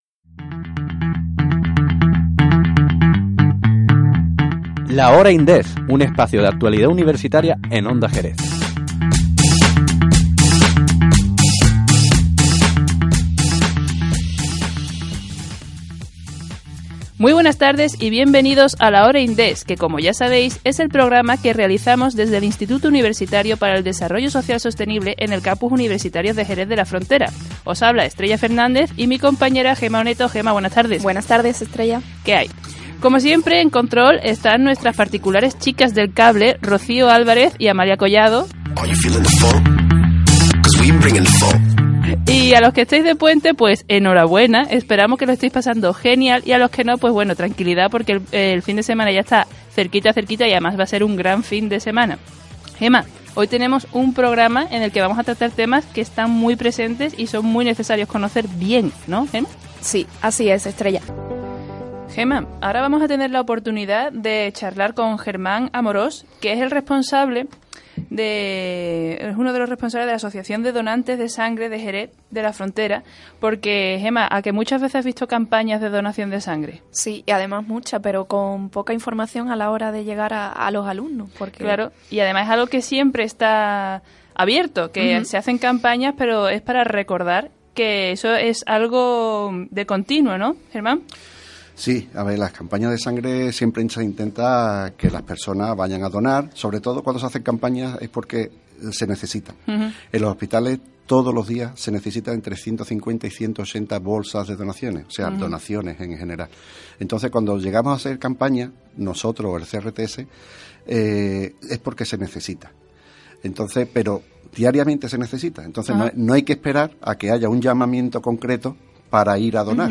Entrevista_INDESS.mp3